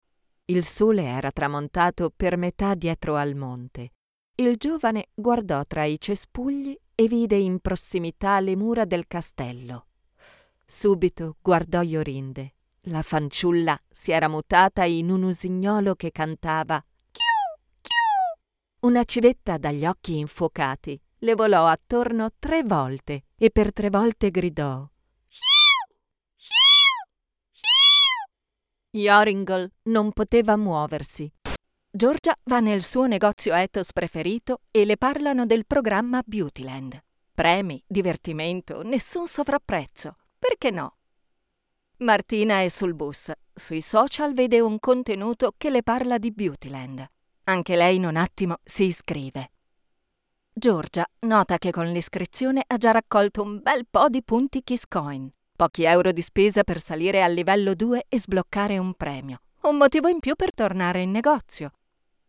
Narration
I built a home studio with audio silent cabin where I log every day.
DeepMezzo-Soprano